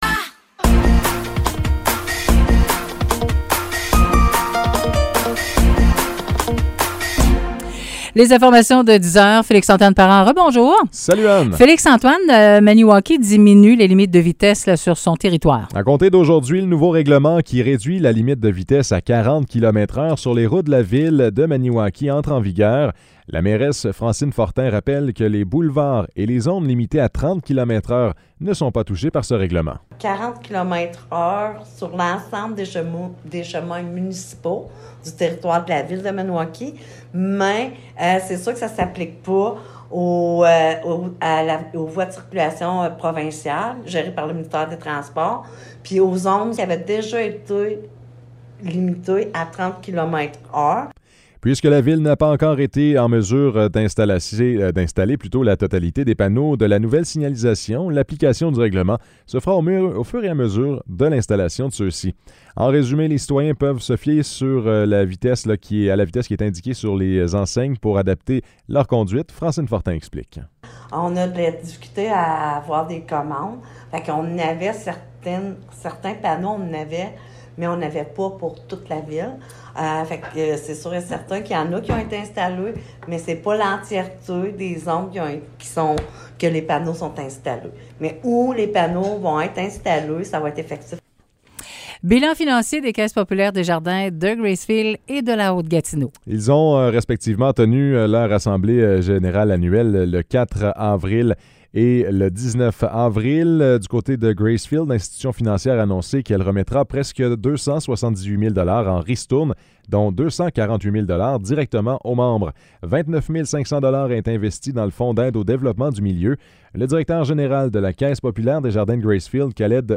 Nouvelles locales - 2 mai 2023 - 10 h